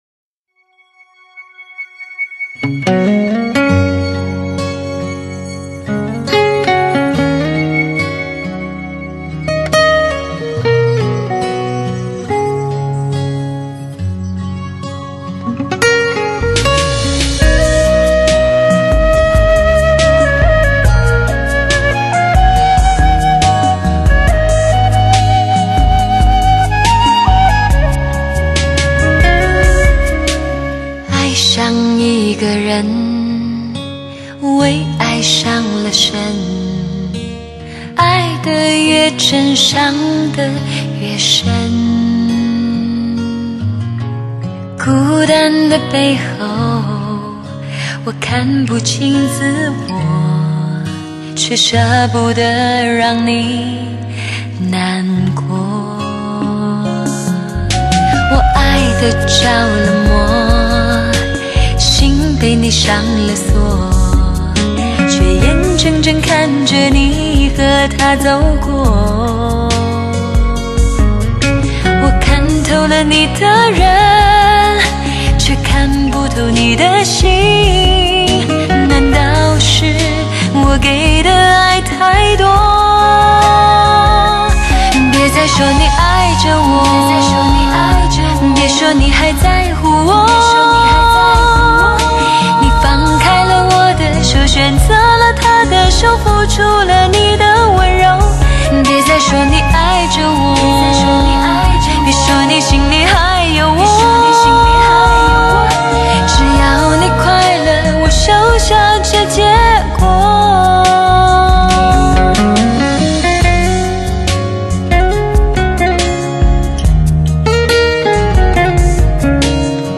德国黑胶HD-CD
A2HD全方位360度环绕HI-FI LPCD专业天碟